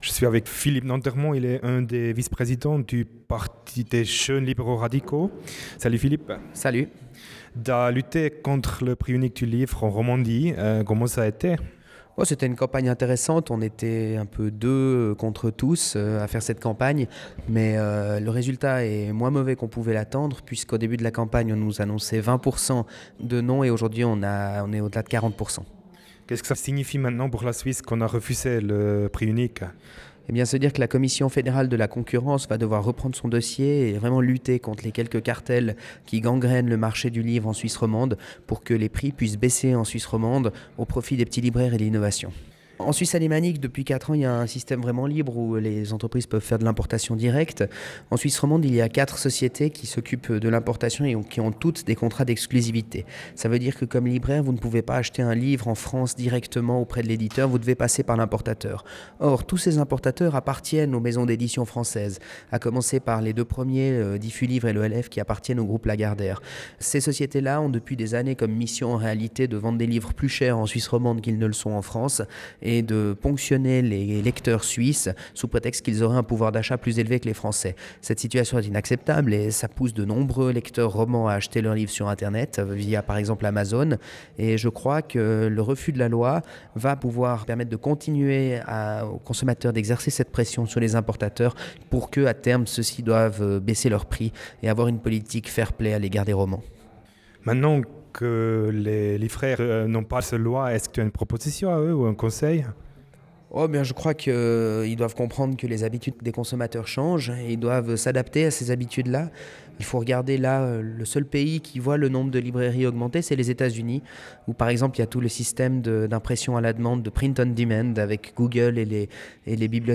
Abstimmungsparty - Philippe Nantermod